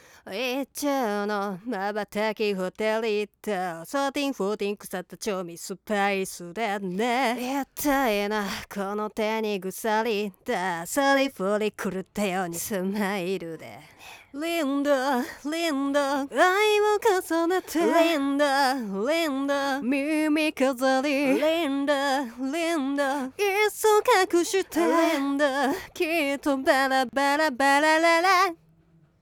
▼こちらが実際に自宅で収録した素音源(MIX前のもの)です。